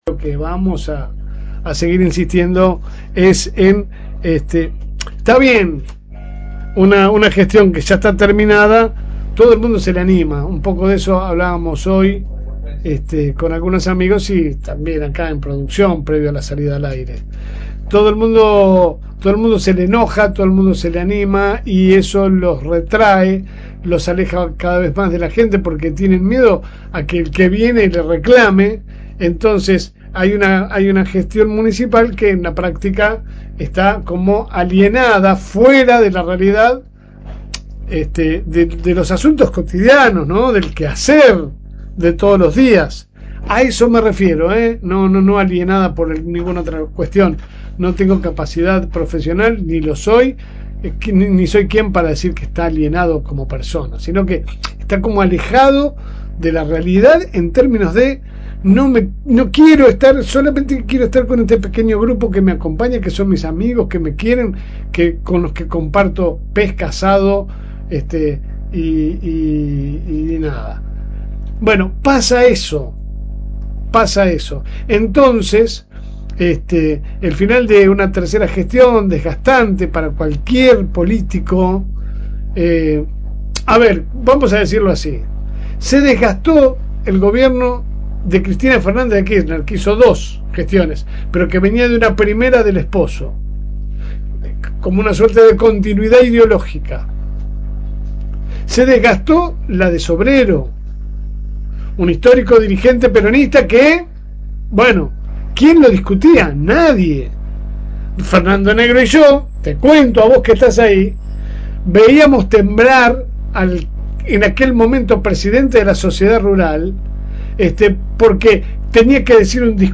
Comentario de LSM